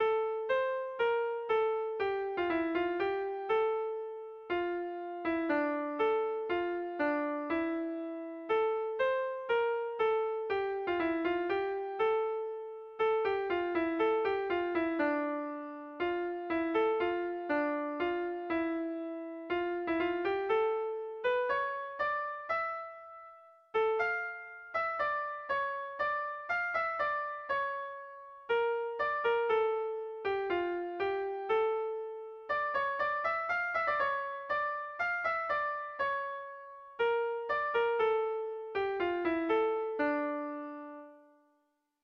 Air de bertsos - Voir fiche   Pour savoir plus sur cette section
Sentimenduzkoa
AABDEF